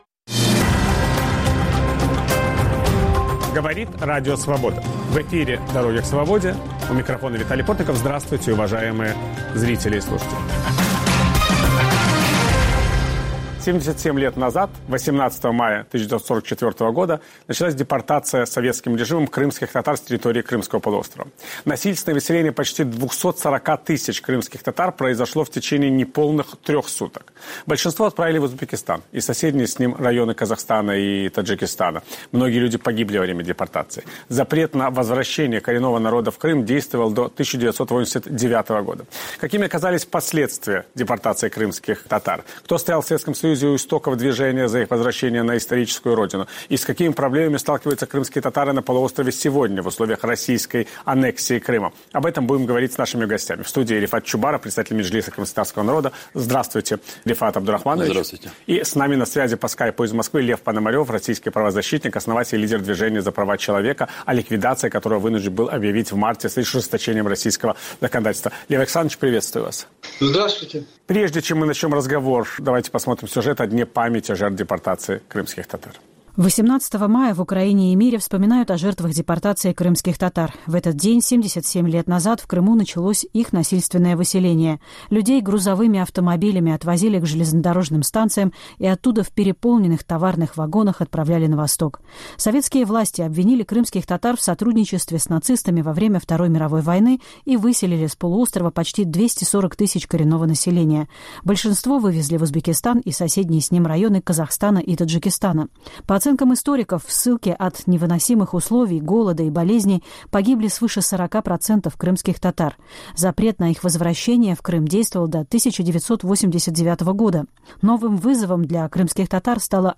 Что происходит с крымскими татарами в аннексированном Крыму? В отмечающийся в Украине 18 мая День памяти жертв геноцида крымскотатарского народа Виталий Портников беседует с председателем Меджлиса крымскотатарского народа Рефатом Чубаровым и российским правозащитником Львом Пономаревым.